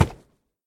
mob / horse / wood2.ogg
wood2.ogg